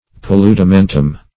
Search Result for " paludamentum" : The Collaborative International Dictionary of English v.0.48: Paludamentum \Pa*lu`da*men*tum\, n.; pl.